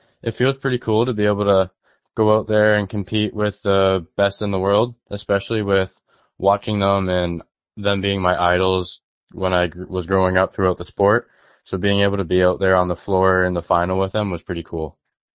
In an interview with Quinte News, he says it is a career highlight.